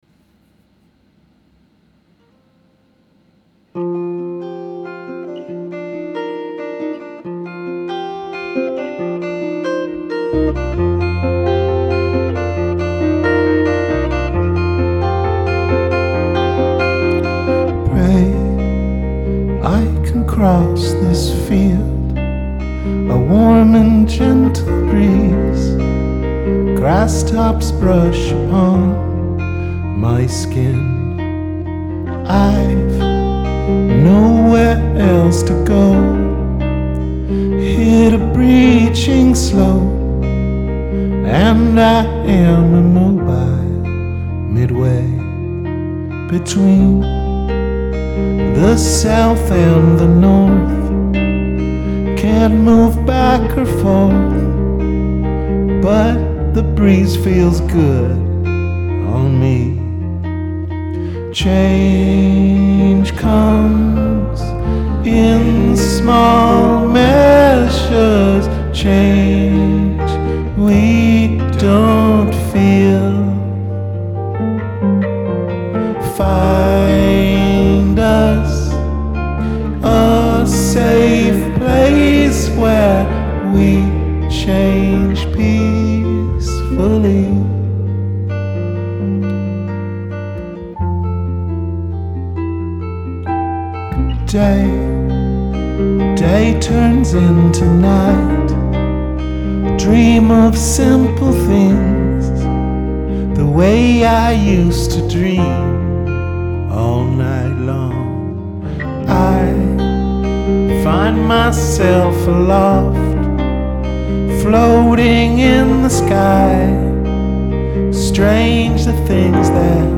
rehearsals 13.3.2012